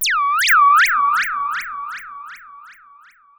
Theremin_FX_03.wav